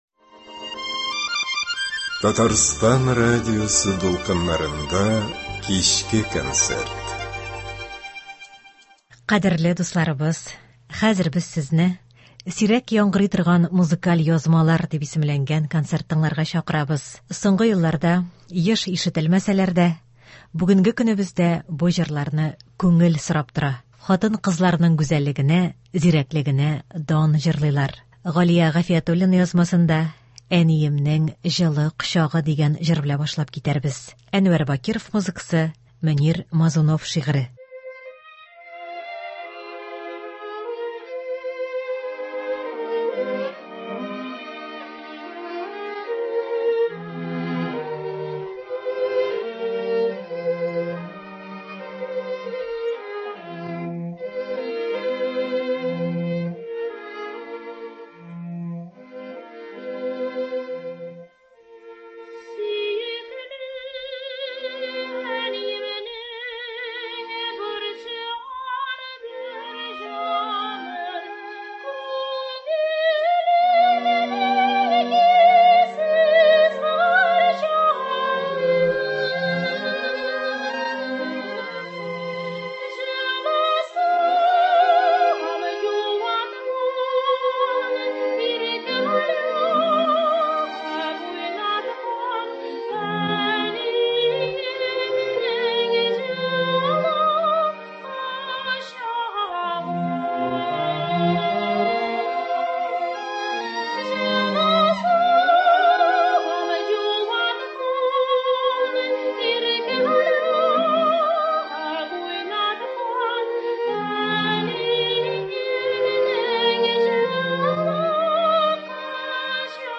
Сирәк яңгырый торган музыкаль язмалар.